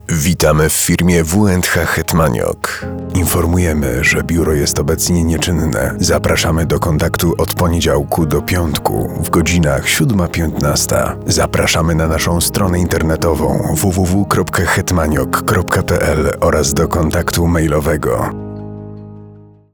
Głos o niskiej intonacji, z subtelnym ciepłem, które wprowadza słuchacza w spokojny, intymny nastrój. Jego niska tonacja nadaje każdemu słowu charakteru, a głębia brzmienia sprawia, że jest przyjemny w odbiorze.
Centralka telefoniczna: realizacja dla W&H Hetmaniok